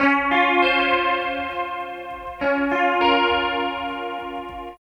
60 GUIT 2 -R.wav